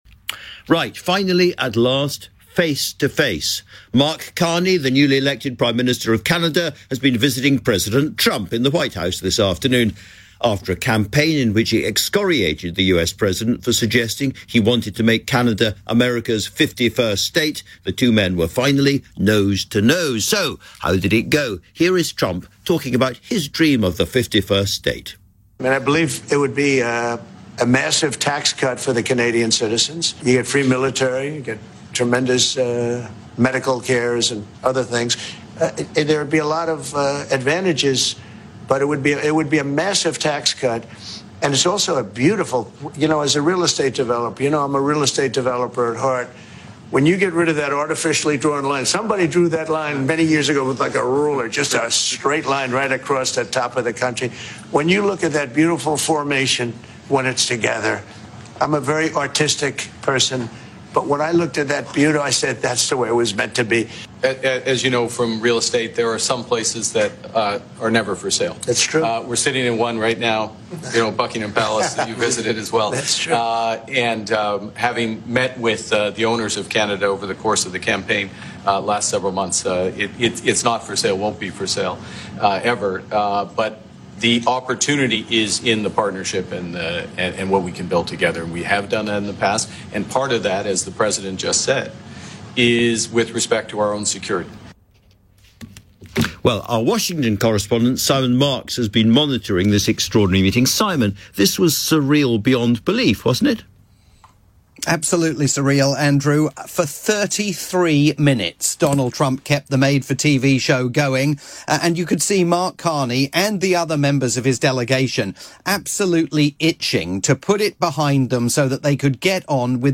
For "Tonight With Andrew Marr" on the UK's LBC.